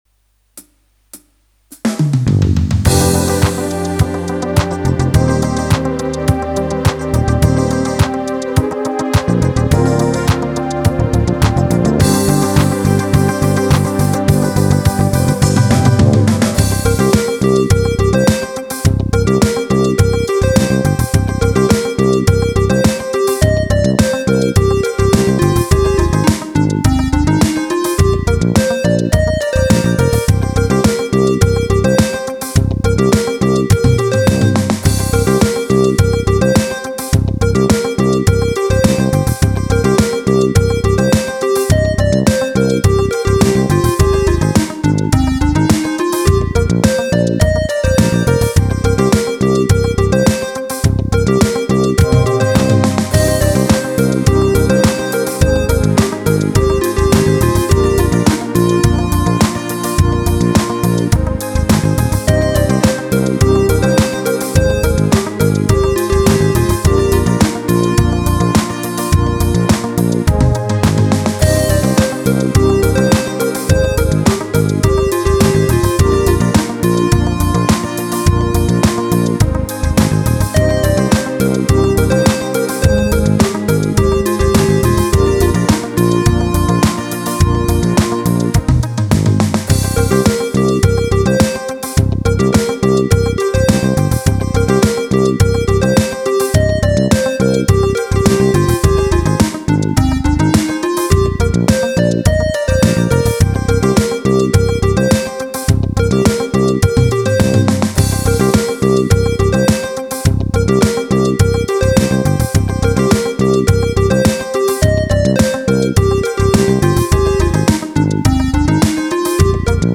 8 Beat
001 Cosmic Pop
Roland E X 10 8 Beat 001 Cosmic Pop Mp 3